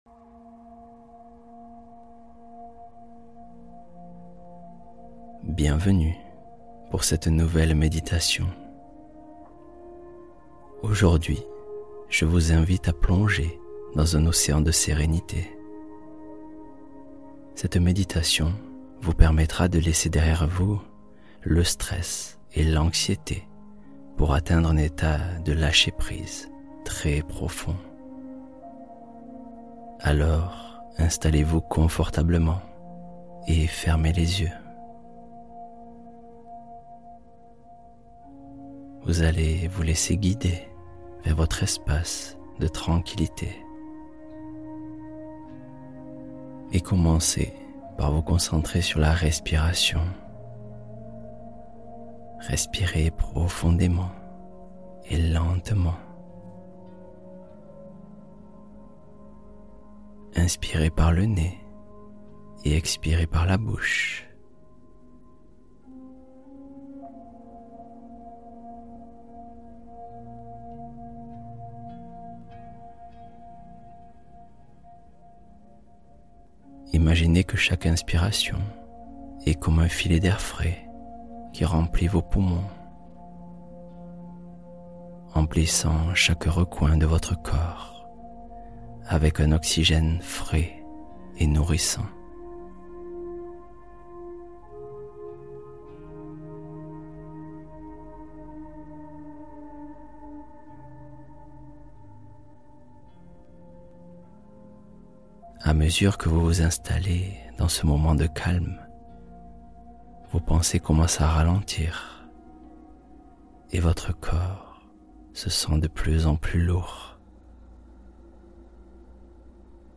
Histoire de Soir : Conte apaisant pour libérer le stress accumulé